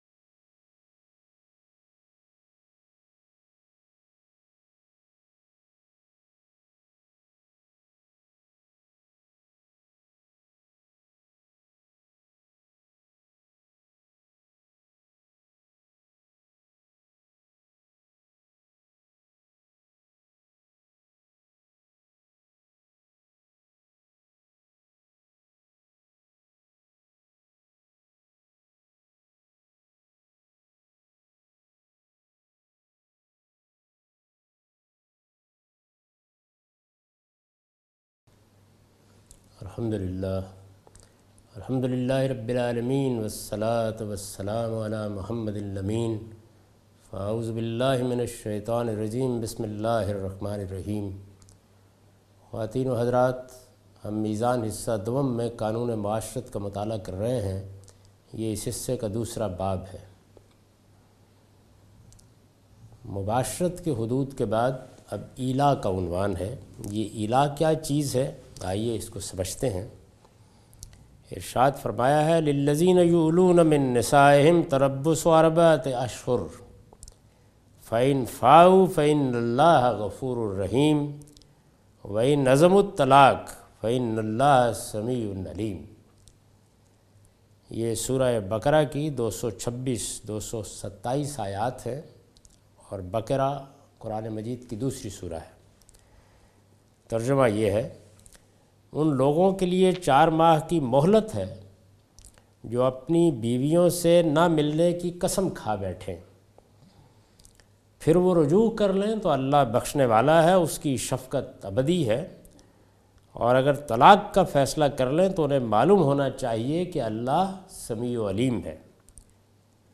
A comprehensive course on Islam, wherein Javed Ahmad Ghamidi teaches his book ‘Meezan’.